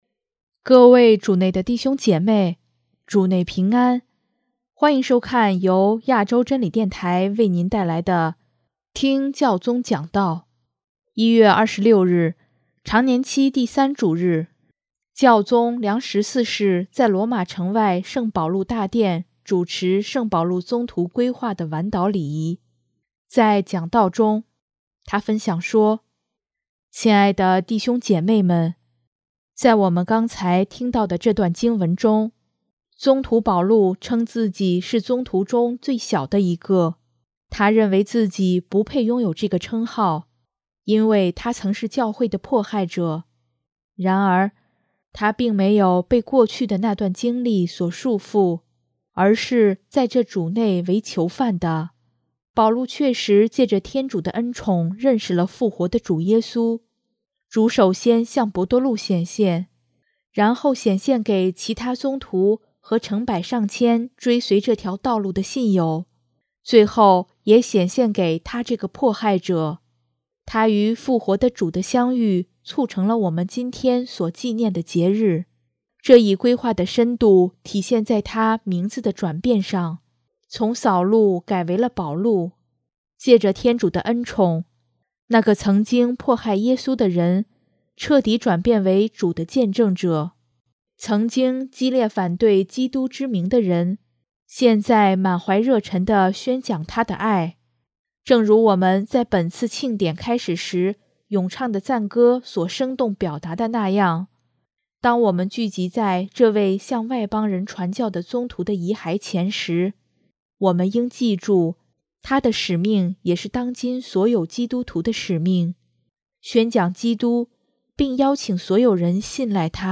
1月26日，常年期第三主日，教宗良十四世在罗马城外圣保禄大殿主持圣保禄宗徒归化的晚祷礼仪，在讲道中，分享说：